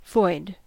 Ääntäminen
US : IPA : [vɔɪd]